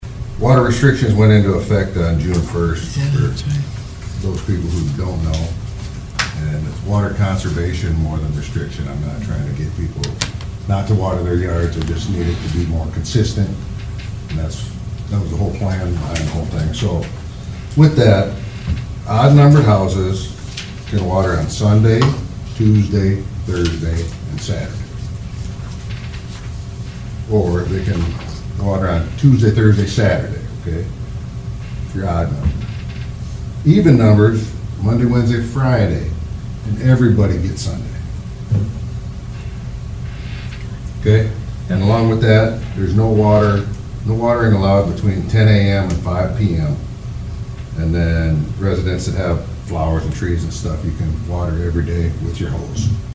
Sewer department spoke before the Huron city commission monday night.